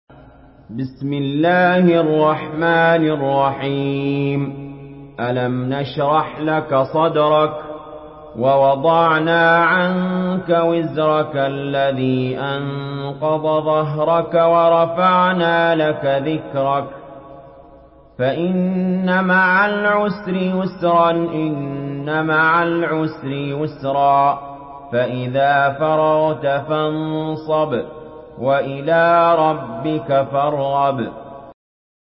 Surah Ash-Sharh MP3 by Ali Jaber in Hafs An Asim narration.
Murattal Hafs An Asim